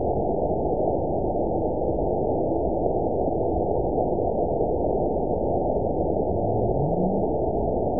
event 920404 date 03/23/24 time 21:37:19 GMT (1 year, 2 months ago) score 9.64 location TSS-AB05 detected by nrw target species NRW annotations +NRW Spectrogram: Frequency (kHz) vs. Time (s) audio not available .wav